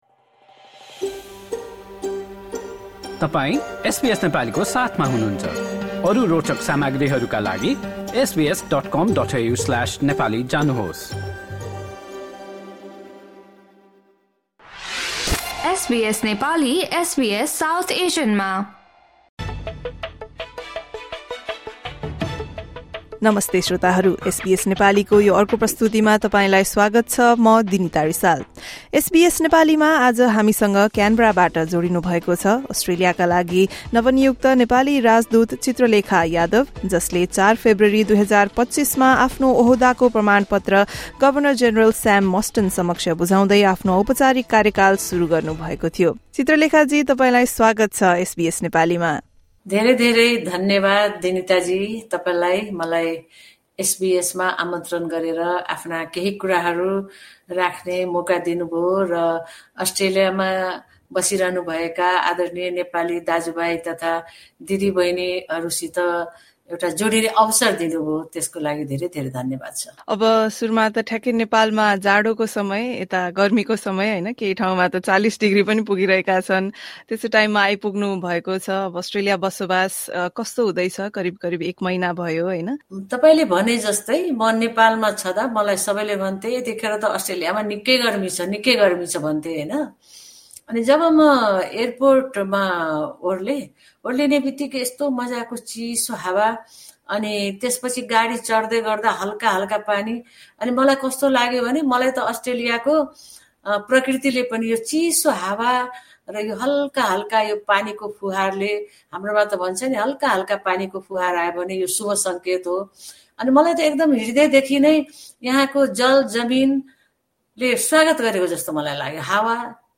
Nepal's new Ambassador to Australia Chitralekha Yadav officially started her duty after presenting her credentials to Governor General Sam Mostyn in Canberra on 4 February 2025. Yadav spoke to SBS Nepali about her priorities, ranging from bilateral relations to development assistance and tourism. Listen to the first part of the conversation.